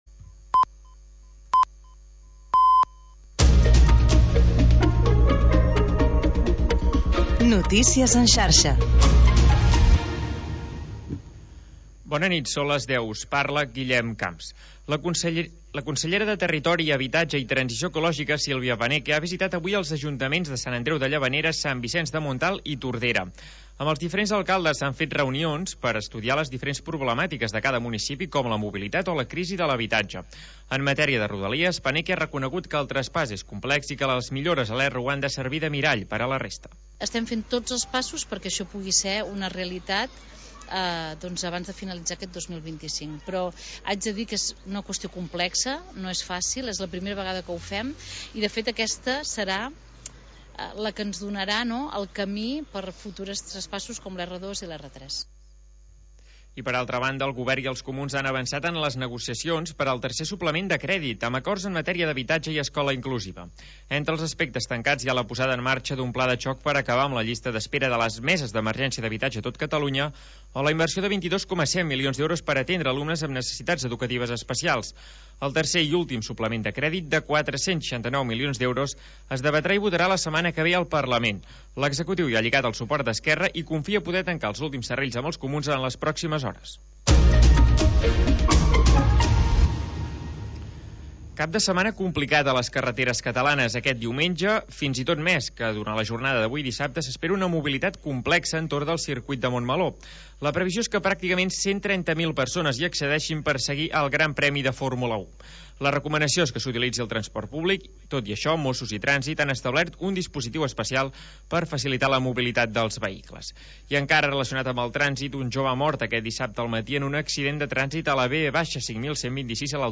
Selecció musical de Dj.